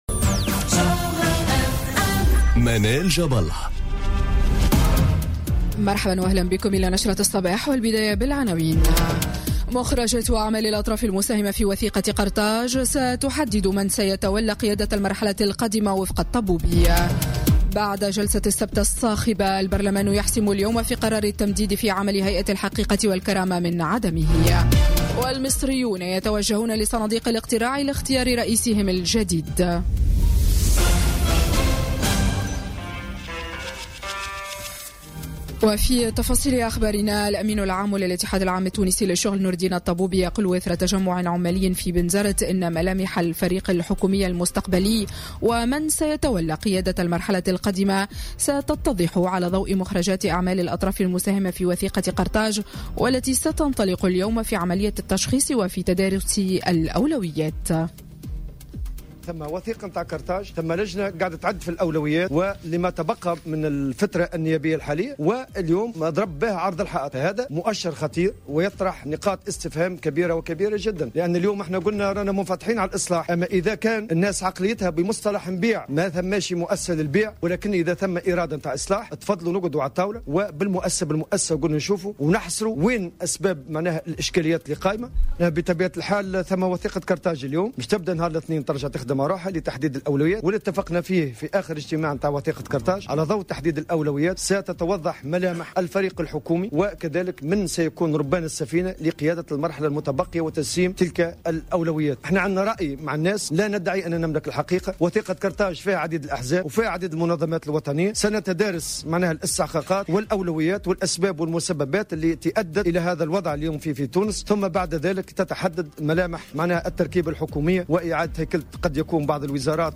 نشرة أخبار السابعة صباحا ليوم الإثنين 26 مارس 2018